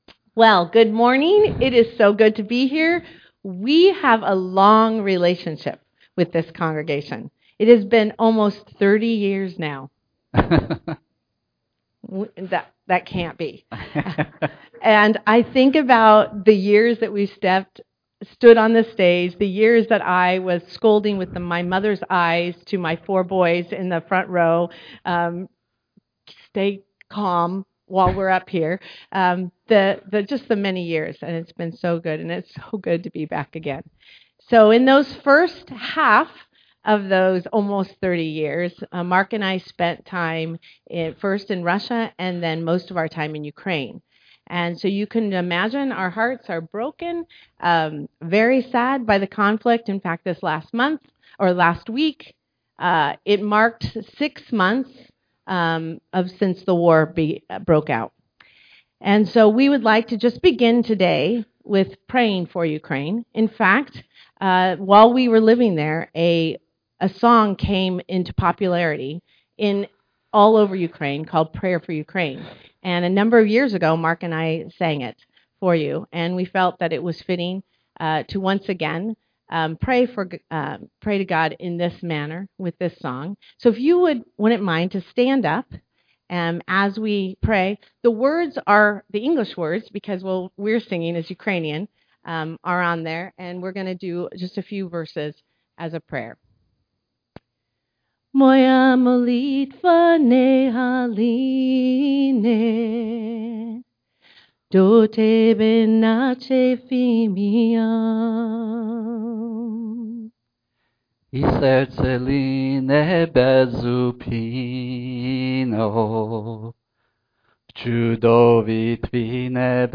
Principles from Nehemiah give us insight into how we work together for the spread of the Gospel. Our apologies on the sound quality at the beginning of the recordings. It gets corrected part way through.